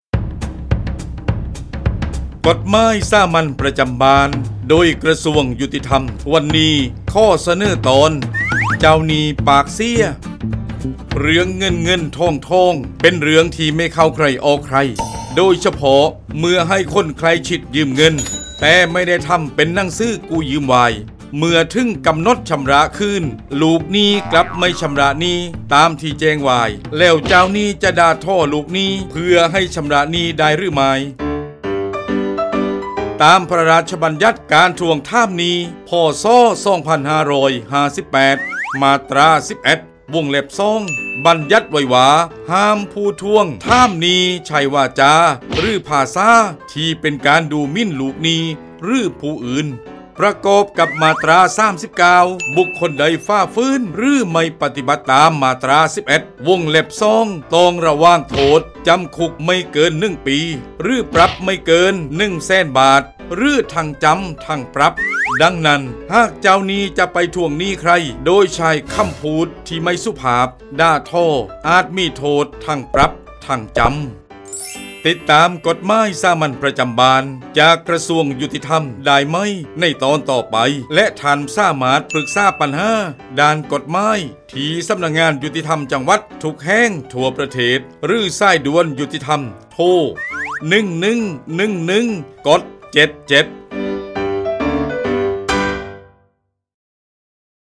กฎหมายสามัญประจำบ้าน ฉบับภาษาท้องถิ่น ภาคใต้ ตอนเจ้าหนี้ปากเสีย
ลักษณะของสื่อ :   บรรยาย, คลิปเสียง